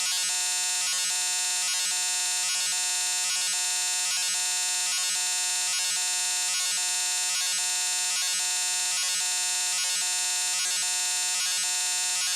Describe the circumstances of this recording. reduced volume, previous file was too loud